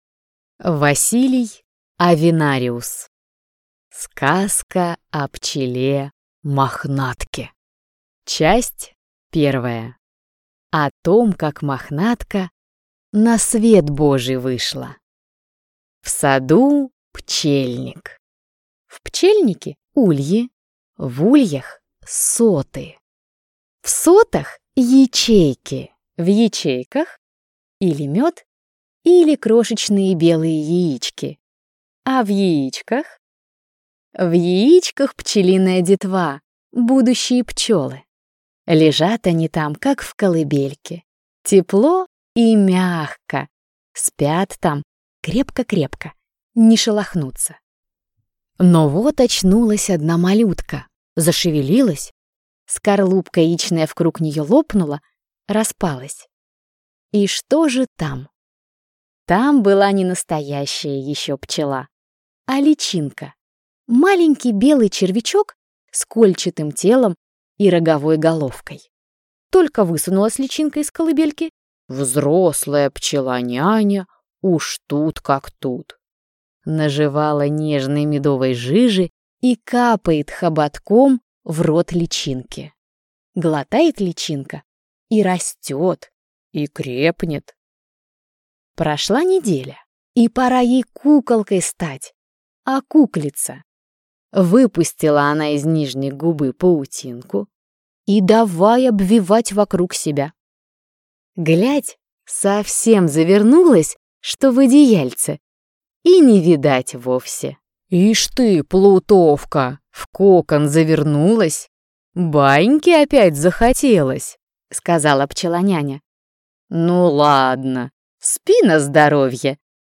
Аудиокнига Сказка о пчеле Мохнатке